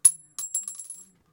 casingfall1.ogg